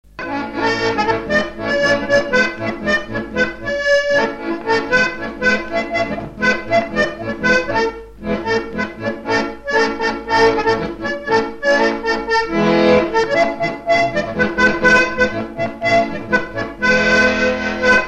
Mémoires et Patrimoines vivants - RaddO est une base de données d'archives iconographiques et sonores.
Chants brefs - A danser
danse : polka des bébés ou badoise
Pièce musicale inédite